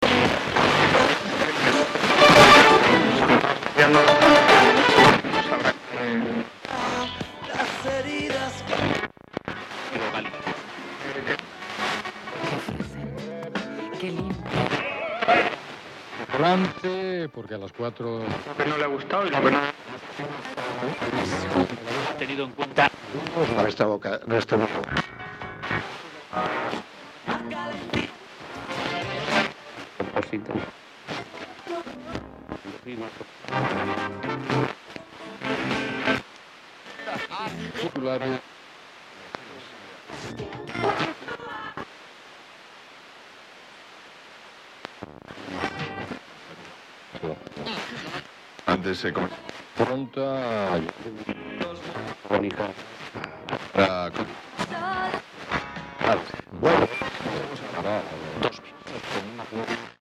Warsaw-orchestra-tunning
concert orchestra poland tunning warsaw sound effect free sound royalty free Memes